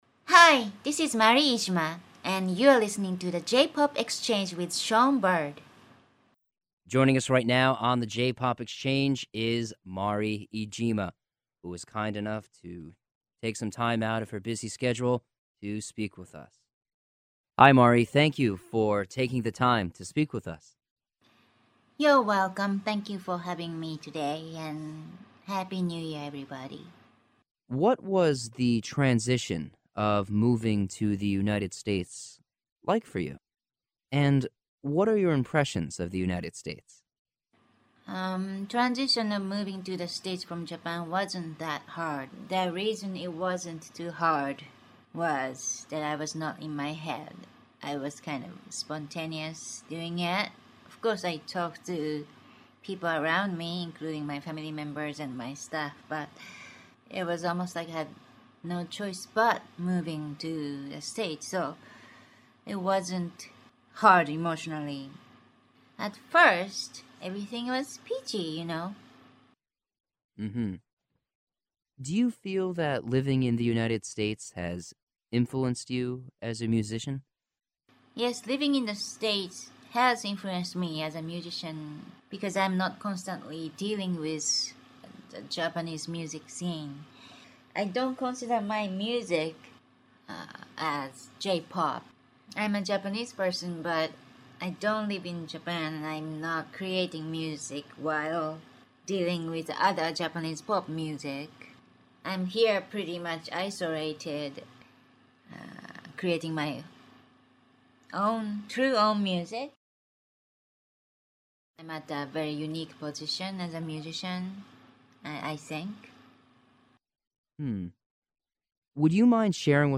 The_JPop_Exchange_Mari_Iijima_Exclusive_Interview.mp3